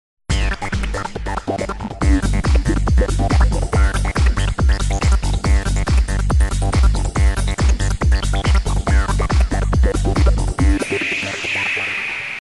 [影视音效][鬼怪特殊跳跃声音][剪辑素材][免费音效下载]-8M资料网
本作品内容为鬼怪特殊跳跃声音， 格式为 mp3， 大小1 MB， 源文件无广告，欢迎使用8M资料网，为维护知识版权生态，如您认为平台内容存在版权争议，请通过官方反馈渠道提交书面权利通知，我们将在收到有效文件后依法及时处理。